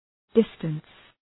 Shkrimi fonetik
{‘dıstəns}